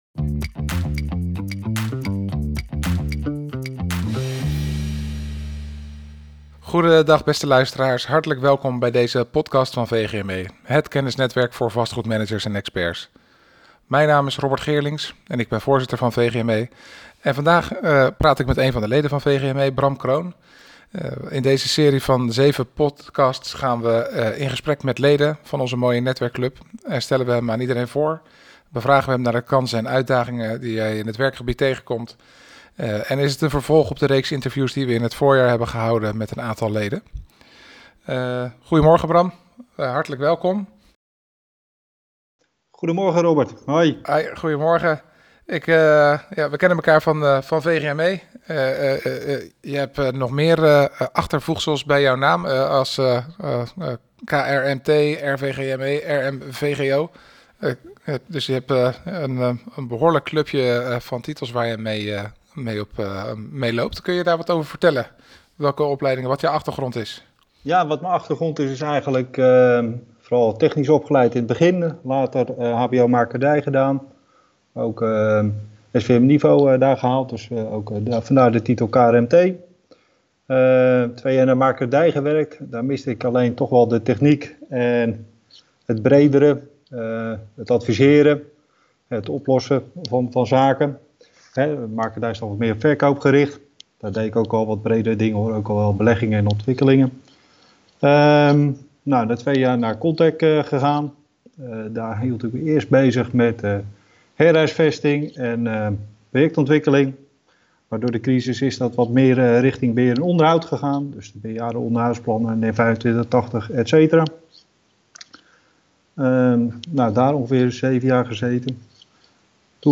De podcasts zijn opgenomen tijdens de lockdown van 2021.
Van sloop tot hergebruik, circulariteit bij de bouwer Interview